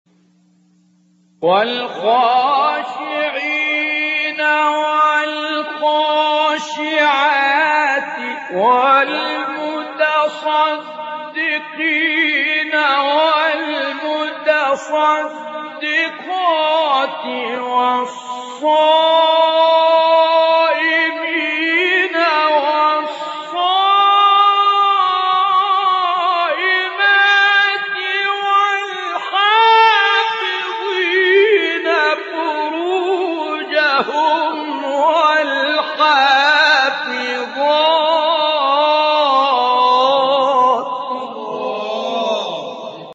گروه شبکه اجتماعی: مقاطع صوتی از سوره احزاب با صوت سید متولی عبدالعال ارائه می‌شود.
به گزارش خبرگزاری بین المللی قرآن (ایکنا) فرازهایی صوتی از سوره مبارک احزاب با صدای سید متولی عبدالعال، قاری به نام مصری در کانال تلگرامی تلاوت ناب منتشر شده است، در زیر ارائه می‌شود.